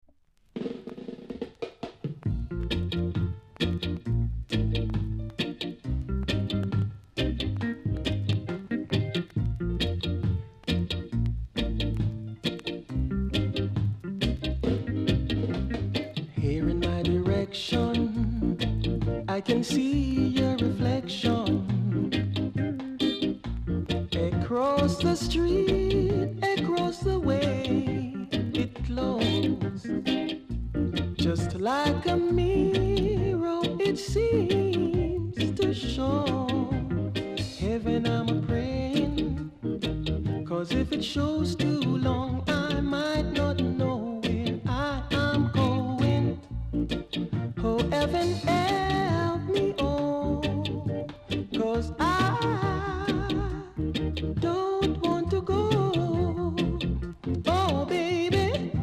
※小さなチリ、パチノイズが少しあります。